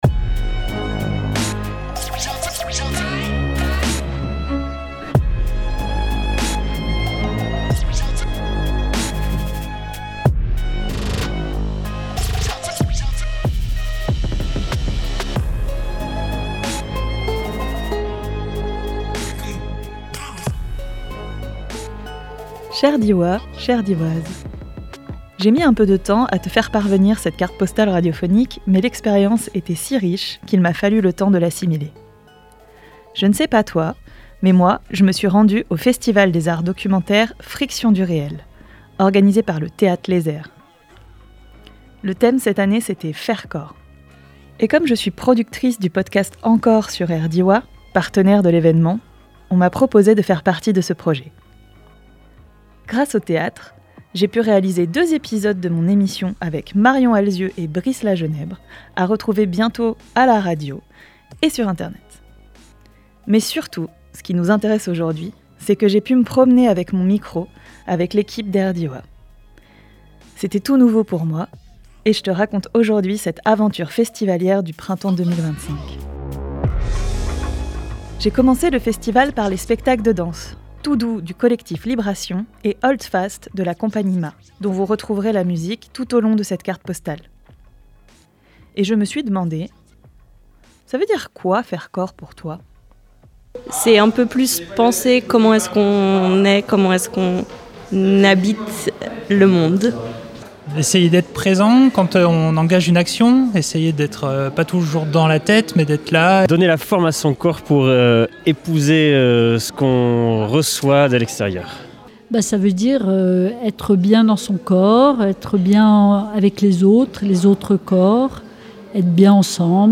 Télécharger en MP3 Dans le cadre du festival de F(r)iction du réel 2025 dont le thème était « Faire corps », l’équipe de RDWA et moi-même avons promené nos micros.
reportage